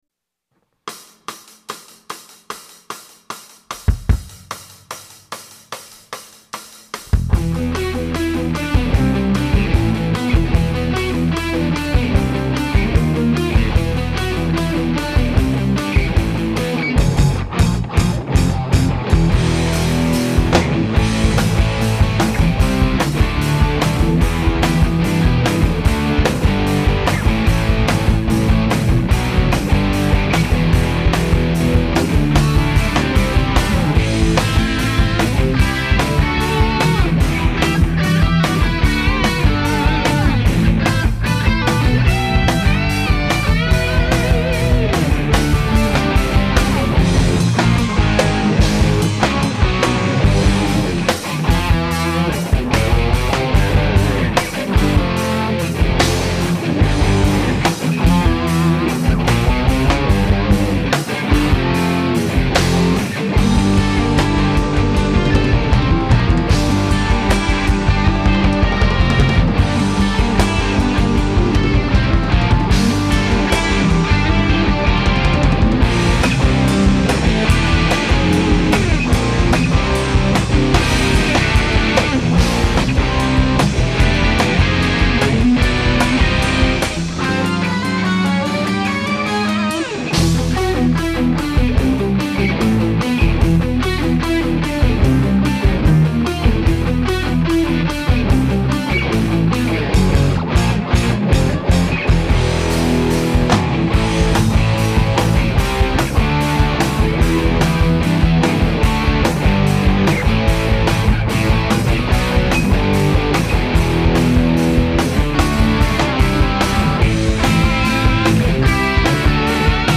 Santa Cruz home studio recordings  (1999)
3 guitars, bass and drums